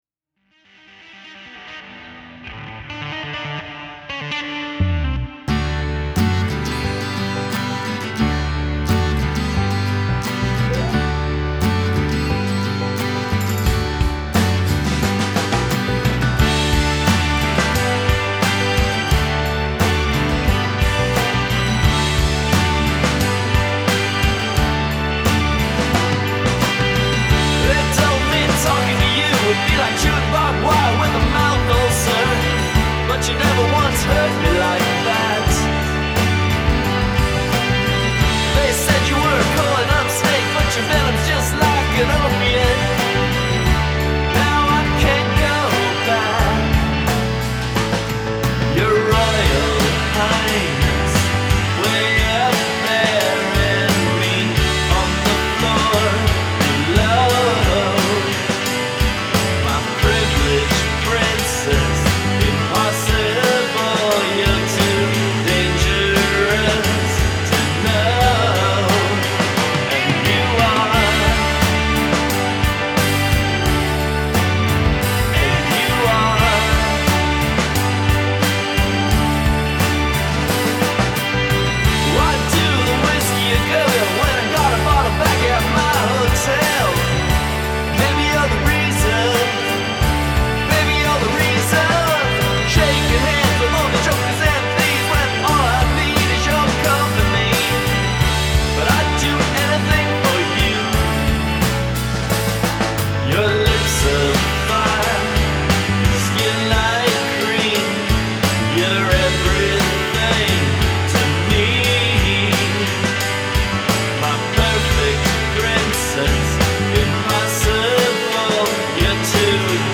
Sharp, creative rocker w/ back story